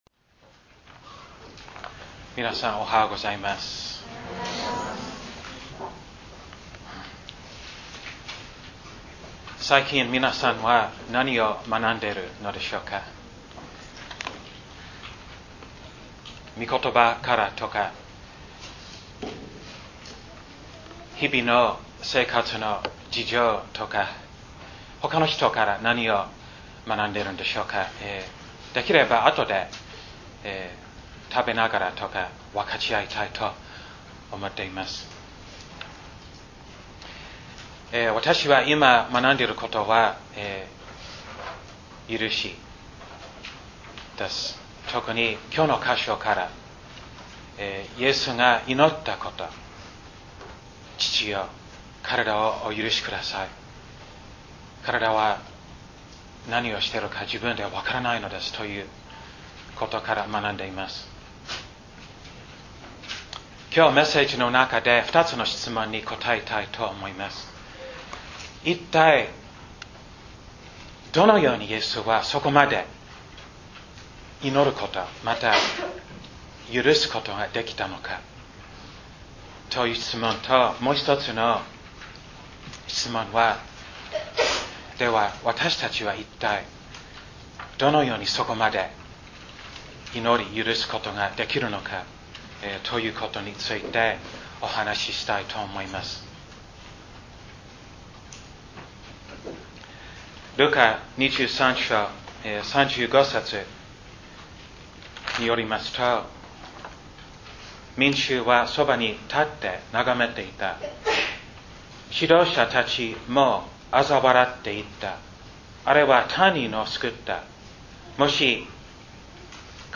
礼拝宣教録音－赦しが必要